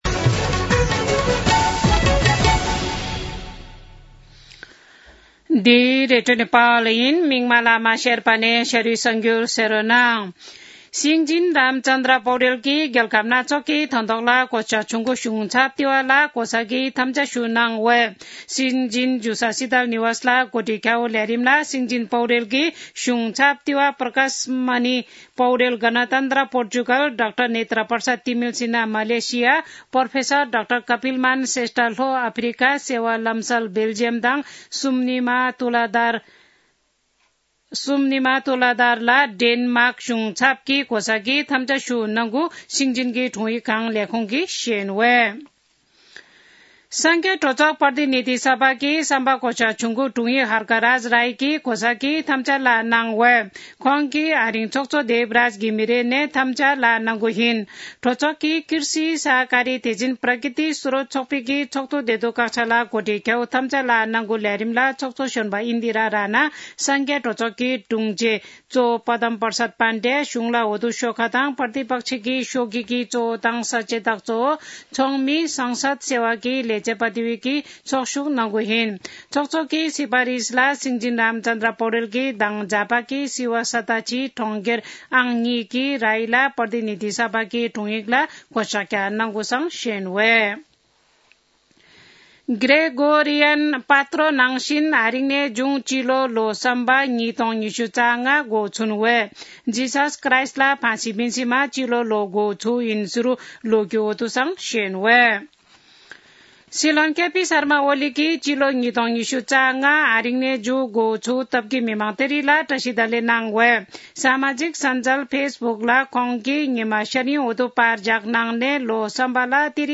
शेर्पा भाषाको समाचार : १८ पुष , २०८१
4-pm-Sherpa-news-.mp3